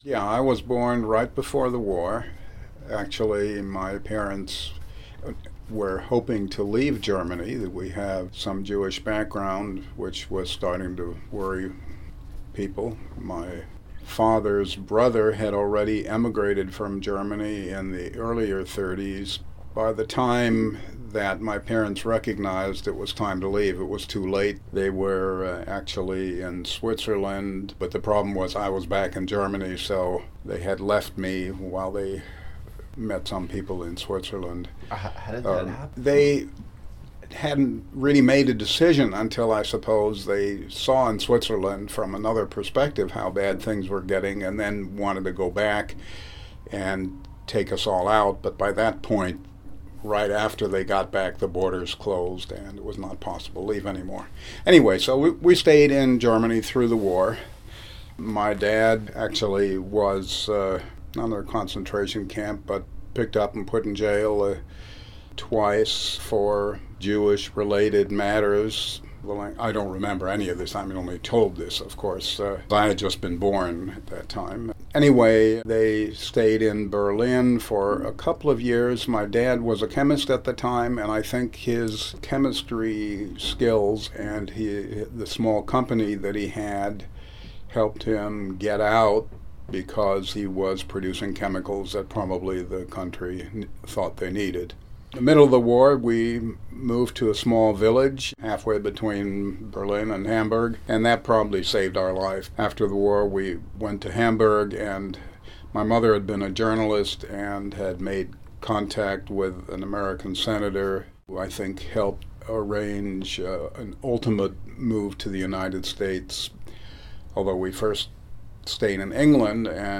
In this excerpt, Dr. Bentler recalls his family’s experience and departure from Germany to England and then to Los Angeles: